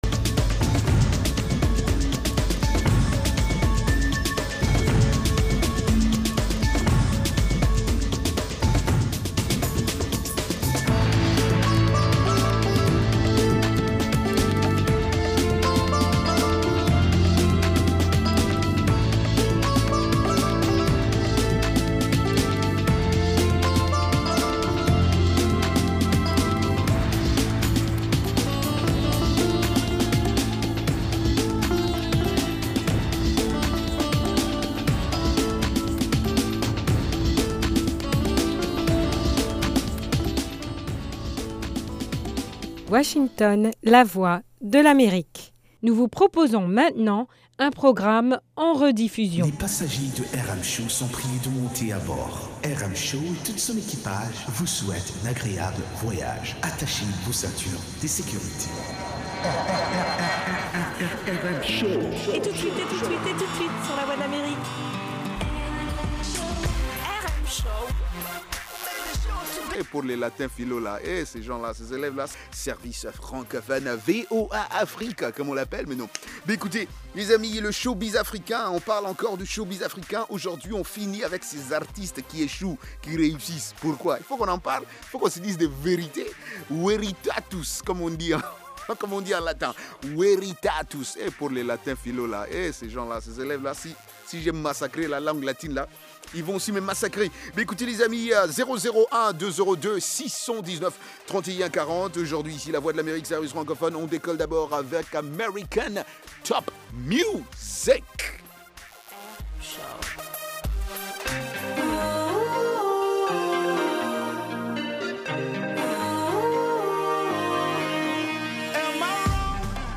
Cette émission est interactive par téléphone.